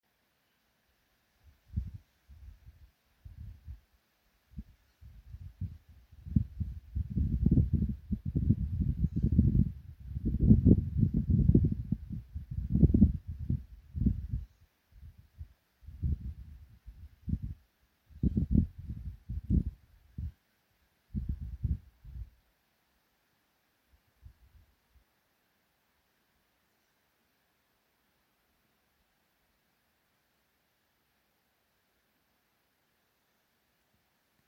Птицы -> Совообразные ->
серая неясыть, Strix aluco
Administratīvā teritorijaDaugavpils novads
Примечания/T. balss un vismaz 1 pull/juv